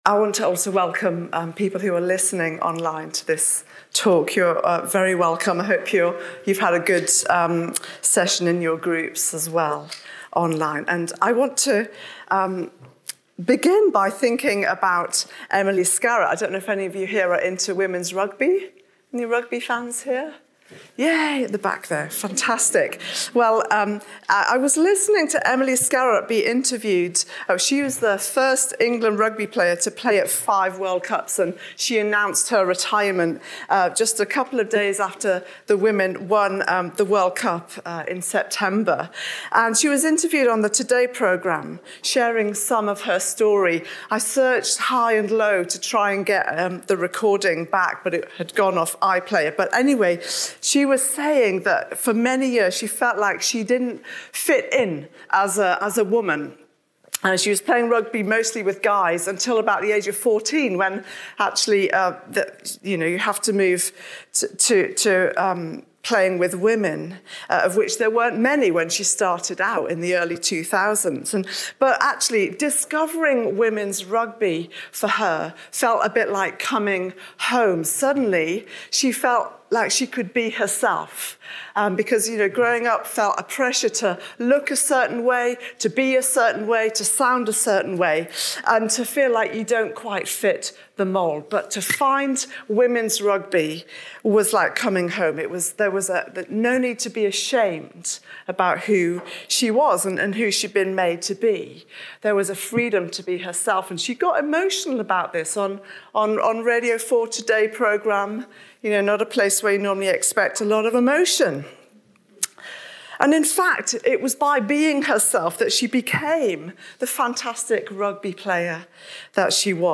📍 Delivered at Find Your Voice 2025.